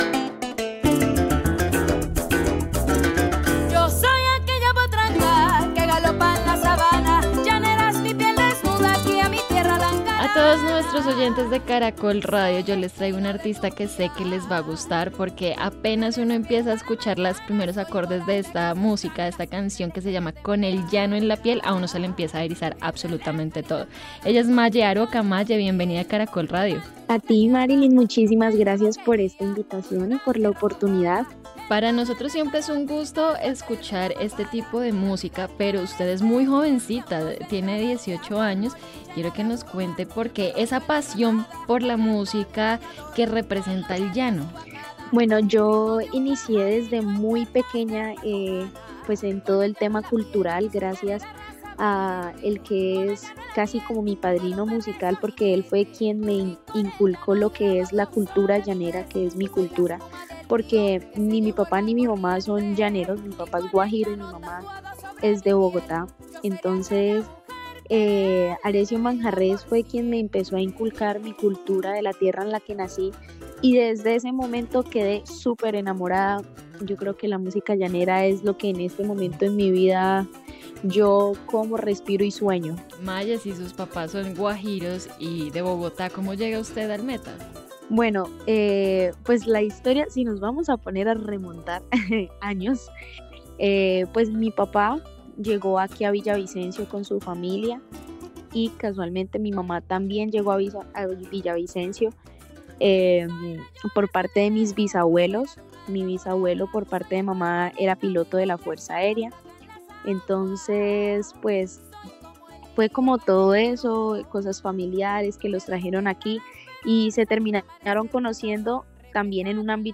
En entrevista con Caracol Radio, la artista contó que desde muy pequeña hizo parte de una escuela en la que la potenciaron como artista integral.